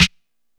Snare (61).wav